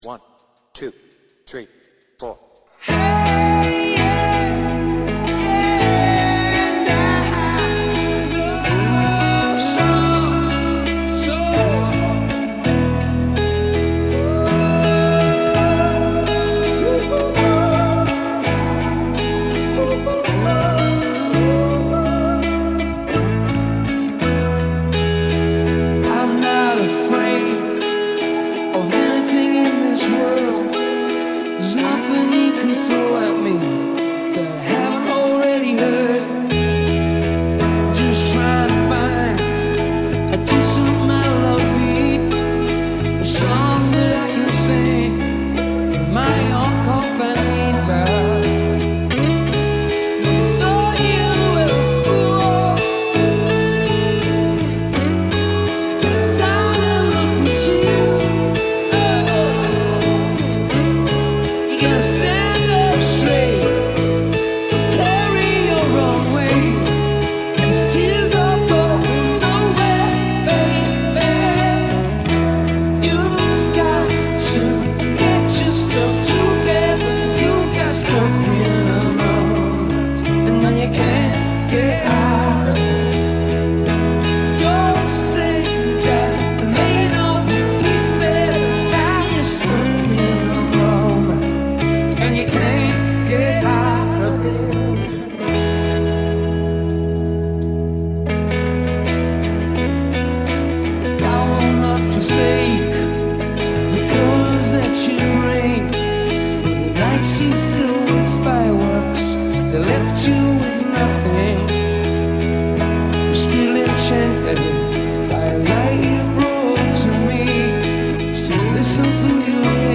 BPM : 83
Tuning : E
With Vocals